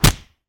Звуки ударов, пощечин
Удар по лицу